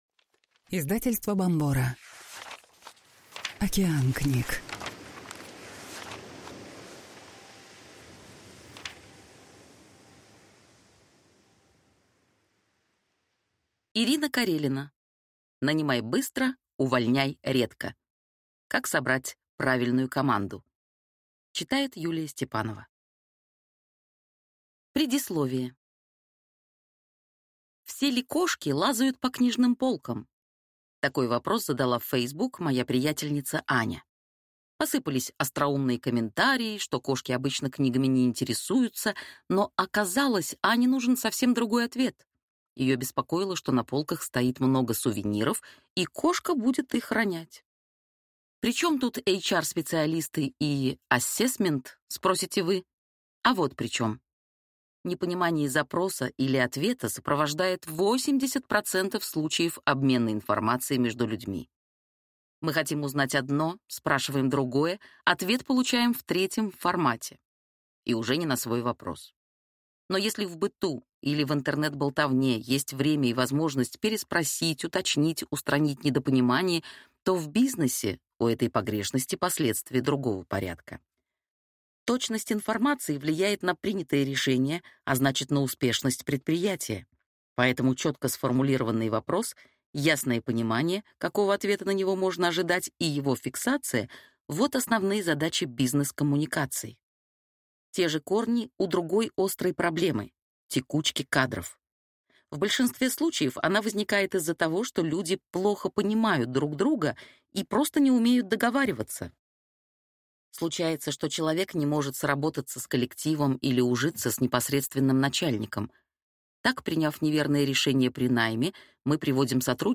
Аудиокнига Нанимай быстро, увольняй редко. Как собрать правильную команду | Библиотека аудиокниг